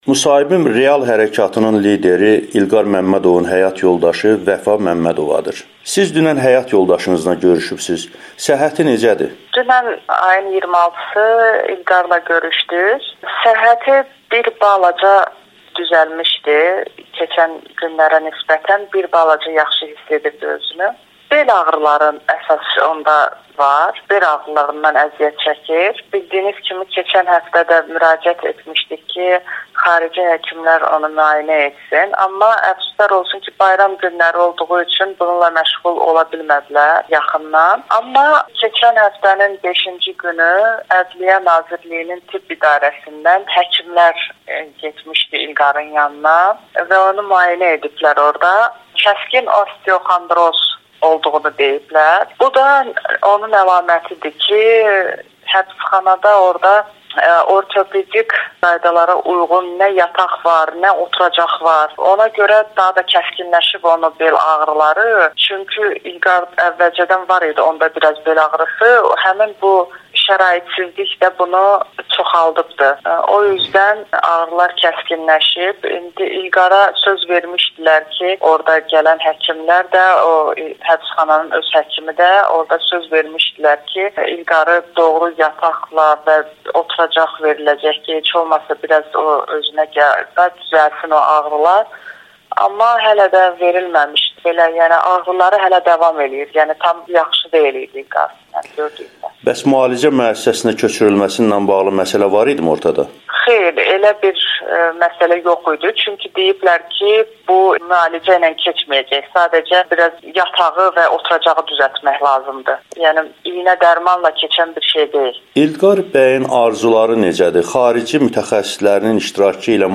müsahibəsi